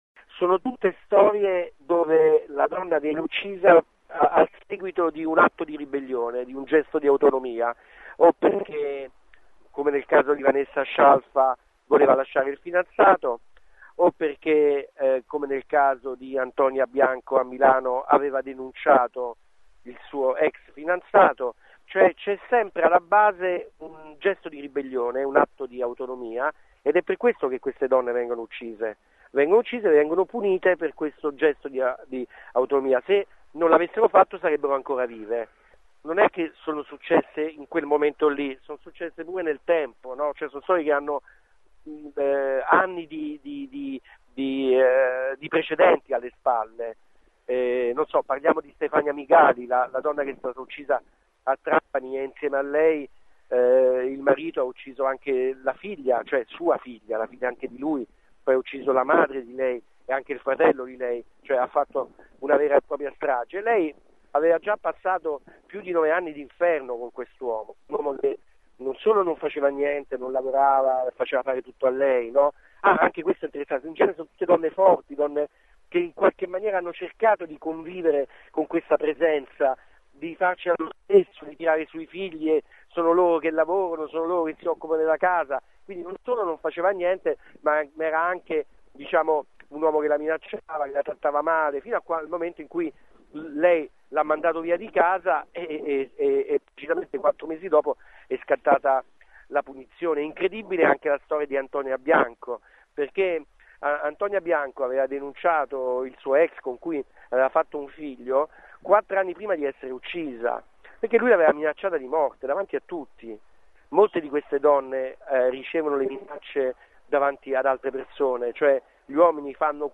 Tutti gli assassinii hanno un filo comune: uomini che non hanno accettato le scelte di autonomia delle donne che poi hanno ucciso. Ascolta l’intervista